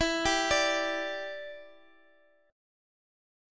G5/E chord